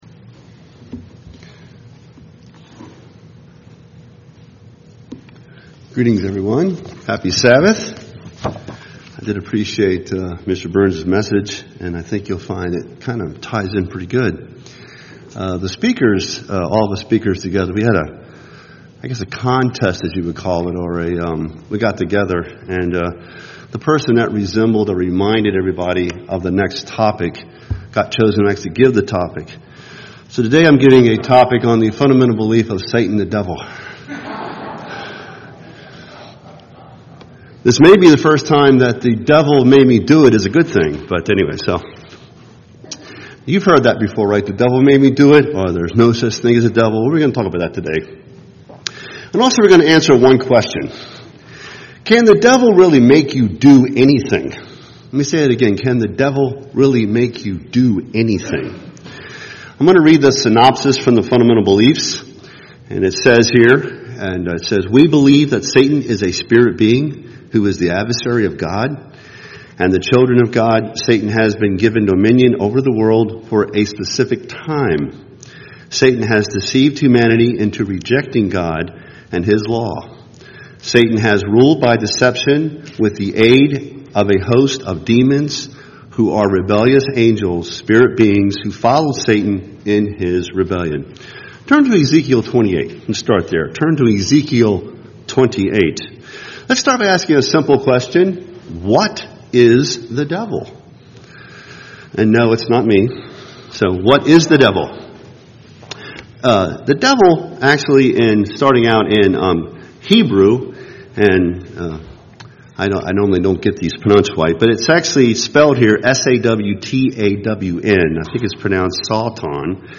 Given in Milwaukee, WI
UCG Sermon Studying the bible?